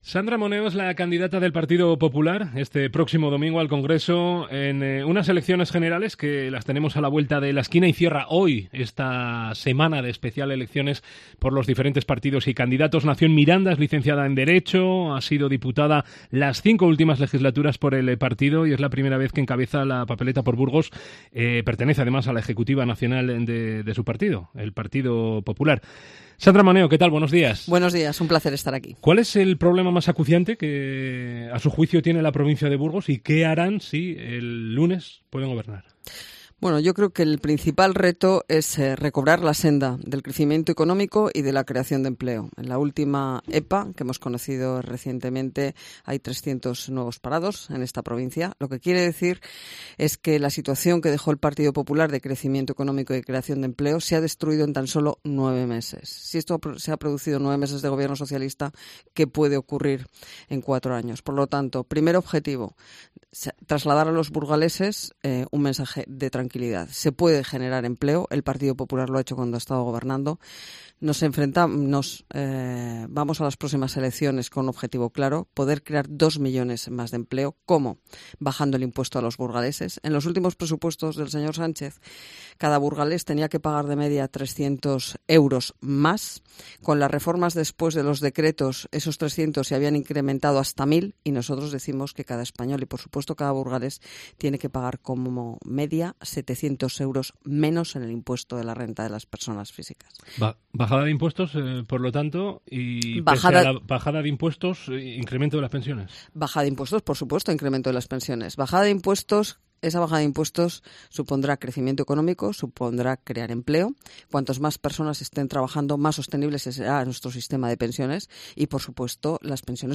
La candidata del PP de Burgos al Congreso de los Diputados, Sandra Moneo, responde a las preguntas